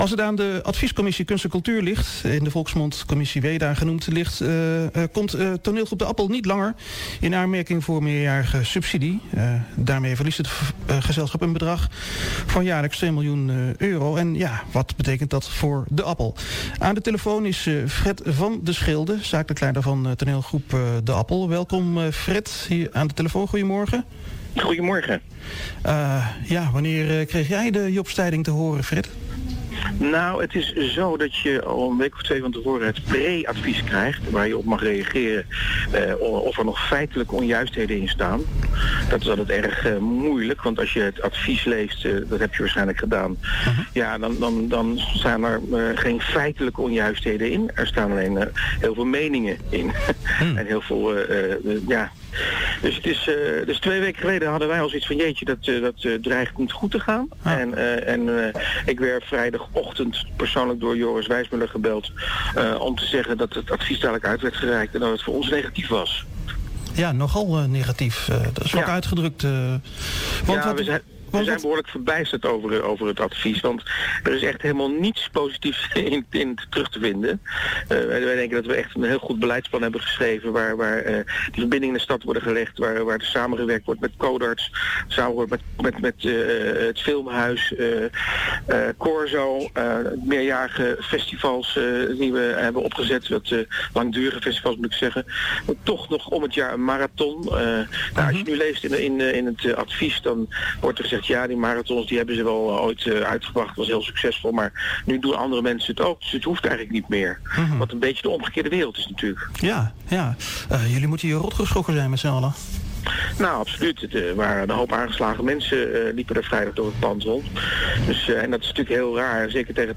telefonisch interview